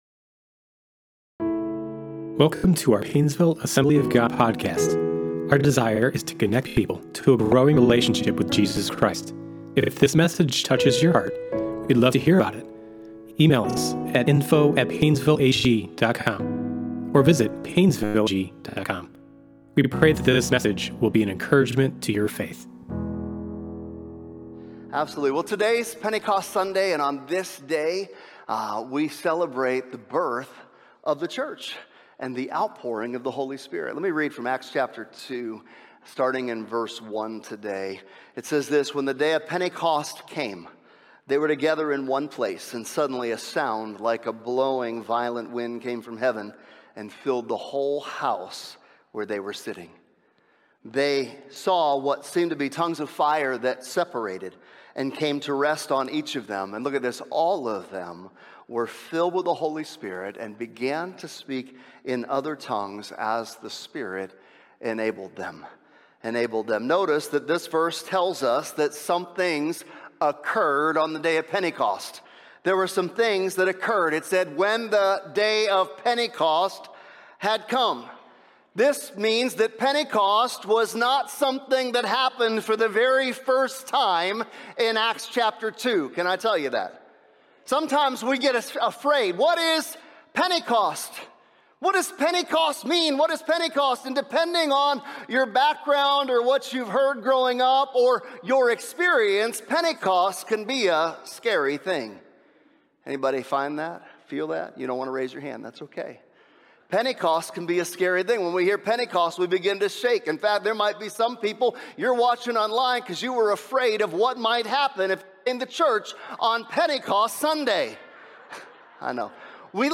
In this Pentecost Sunday message, we dive into Pentecost in the Old Testament, Pentecost in the New Testament, and Pentecost today!